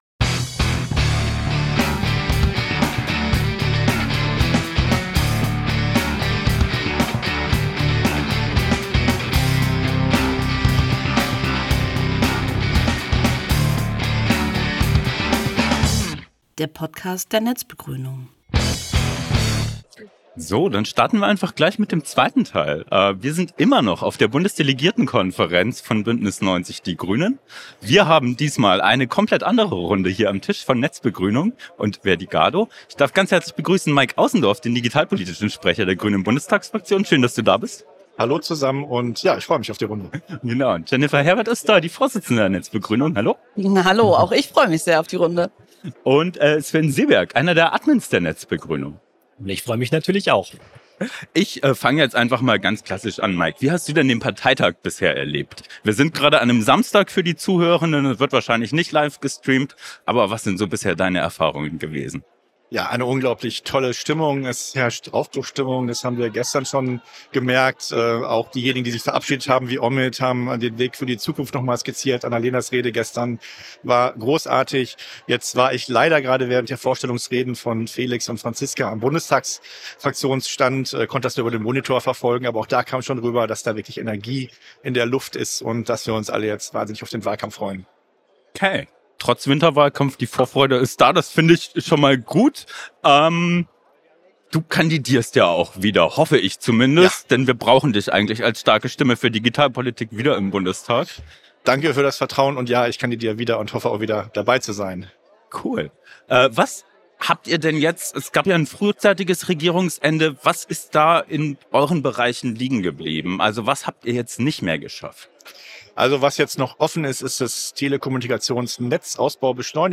Beschreibung vor 1 Jahr Auf der 50. Bundesdelegiertenkonferenz hat die netzbegrünung die Gelegenheit genutzt, drei Podcastfolgen mit Gästen aufzunehmen. In der zweiten Folge sprechen wir mit Maik Außendorf, Mitglied des Bundestags und Sprecher für Digitalpolitik der grünen Bundestagsfraktion. Im Mittelpunkt stehen digitalpolitische Themen auf Bundesebene. Meist ist Digitalpolitik mit vielen anderen Themen verknüpft und so reden wir auch über Erneuerbare Energien, Privatfernsehen und Planungsbeschleunigung.